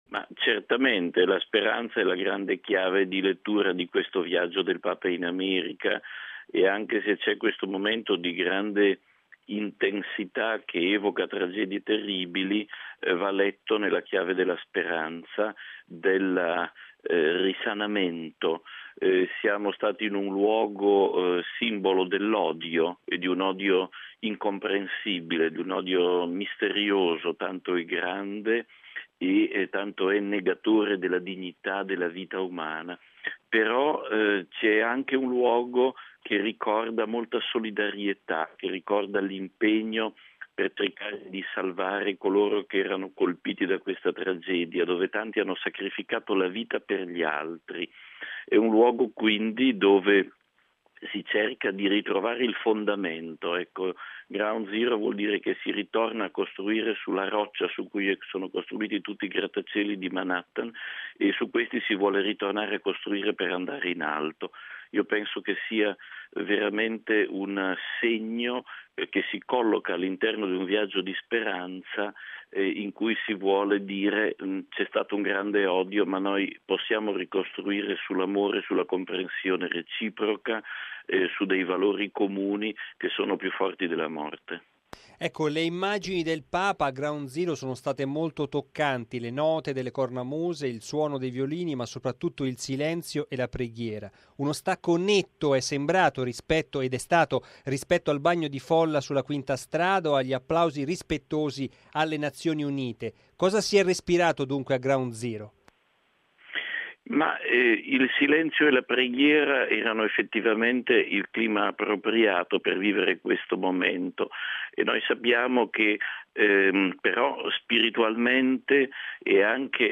raggiunto telefonicamente a New York